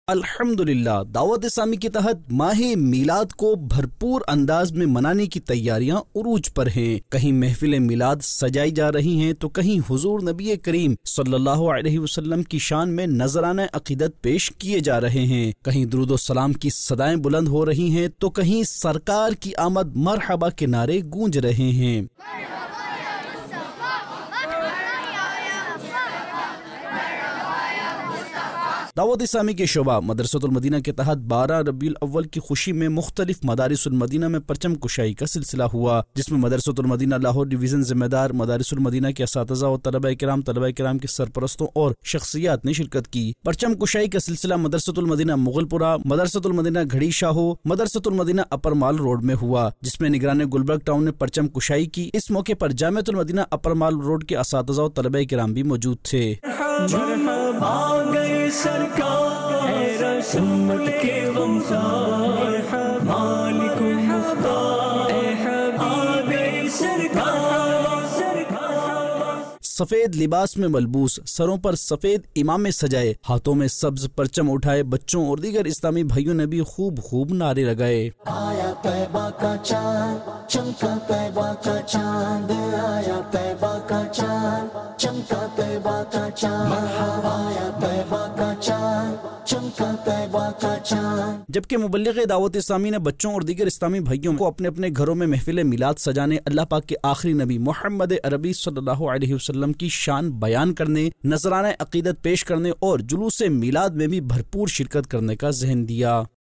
News Clips Urdu - 27 September 2023 - Dawat e Islami Kay Tehat Maah e Rabi ul Awwal Ki Tayyariyan Urooj Par Nov 7, 2023 MP3 MP4 MP3 Share نیوز کلپس اردو - 27 ستمبر 2023 - دعوتِ اسلامی کے تحت ماہِ ربیع الاول کی تیاریاں عروج پر